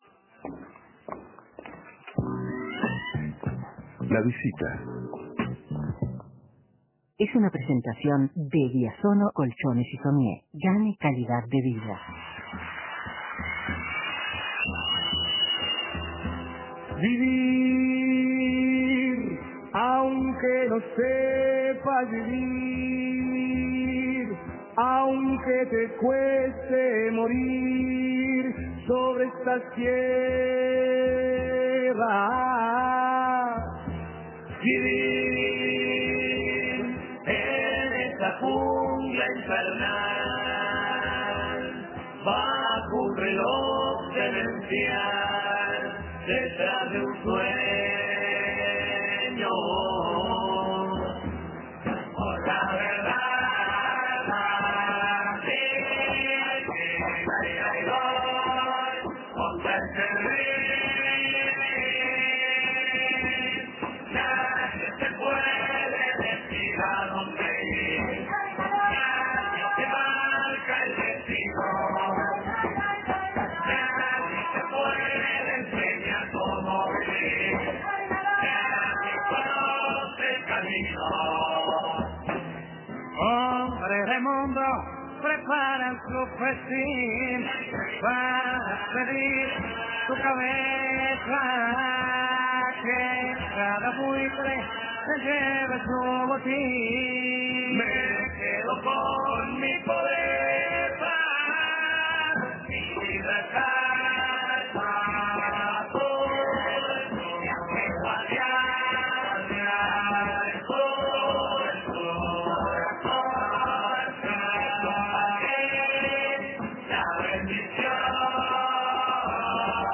Entrevista a Yamandú y Tabaré Cardozo